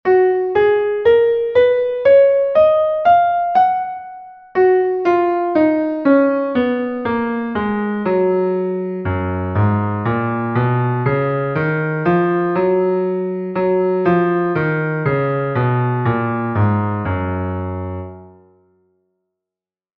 Die Tonart Ges-Dur, ihre Tonleiter aufsteigend und absteigend, notiert im Violinschlüssel und Bassschlüssel.
Ges As B Ces Des Es F
Ges-Dur.mp3